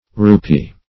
Rupee \Ru*pee"\ (r[.u]*p[=e]"), n. [Hind. r[=u]piyah, fr. Skr.